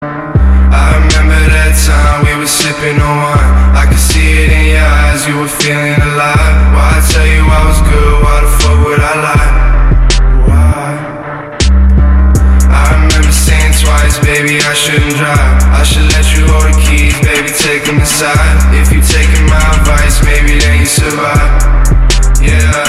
• Качество: 320, Stereo
Хип-хоп
качающие
Bass
депрессивные
Cloud Rap